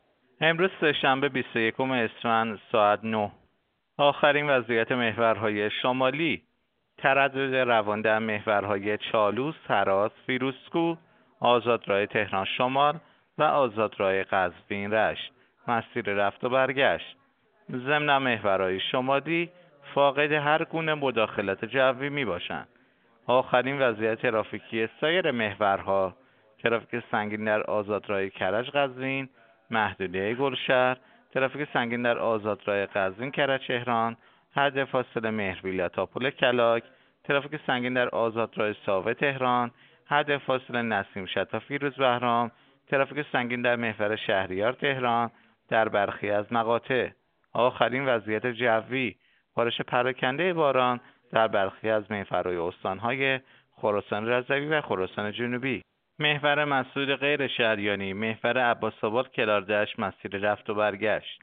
گزارش رادیو اینترنتی از آخرین وضعیت ترافیکی جاده‌ها ساعت ۹ بیست و یکم اسفند؛